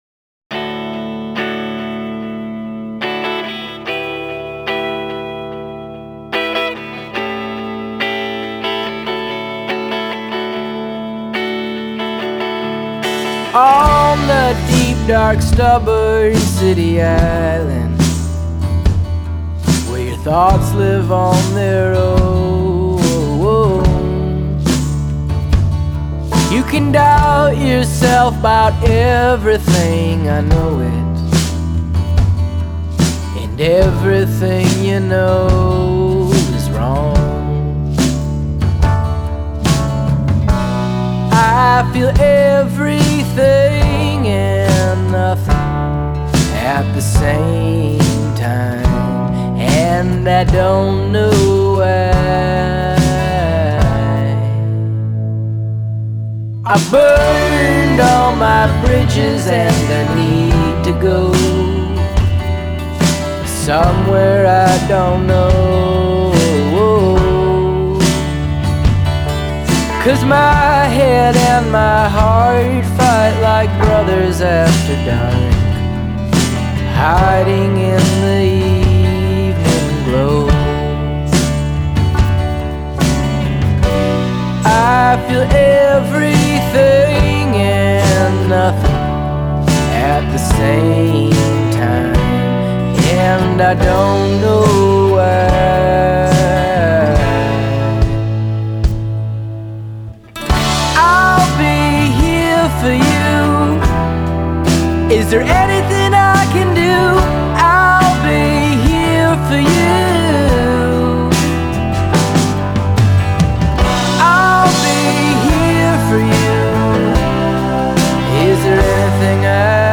Genre: Alt Folk, Americana